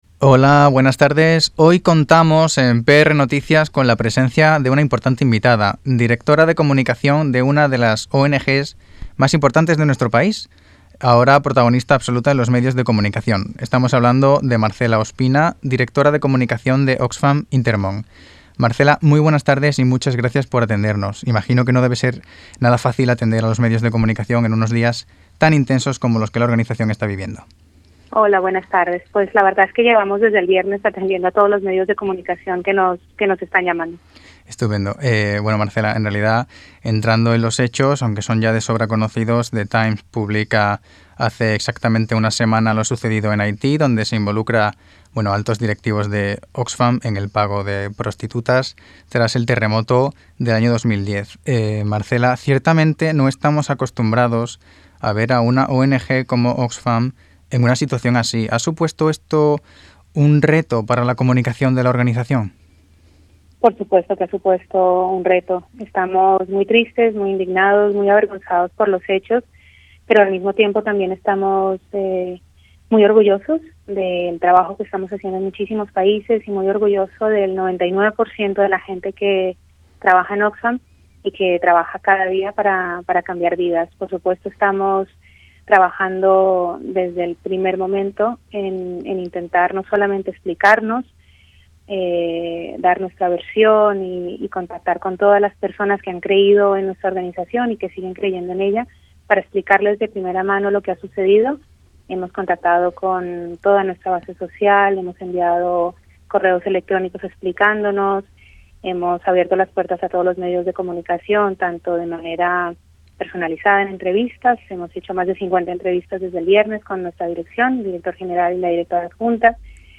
ENTREVISTA_OXFAM.mp3